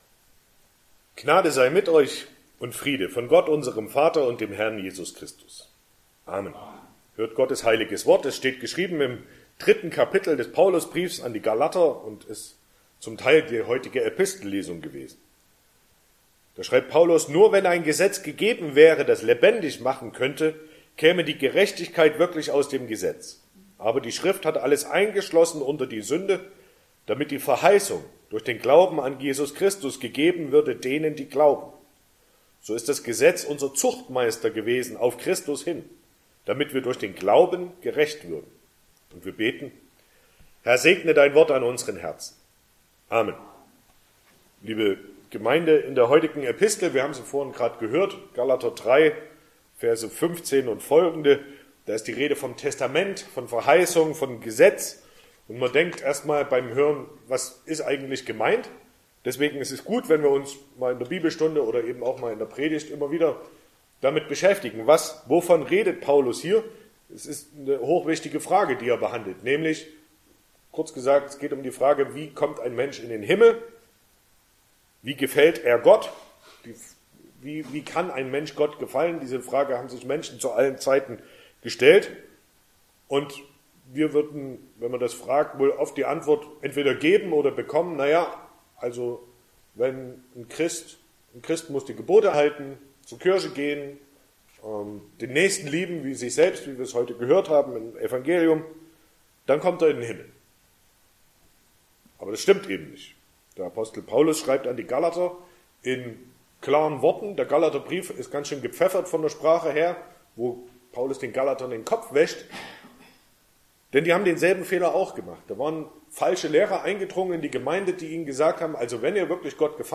Sonntag nach Trinitatis Passage: Galater 3,15-24 Verkündigungsart: Predigt « 15.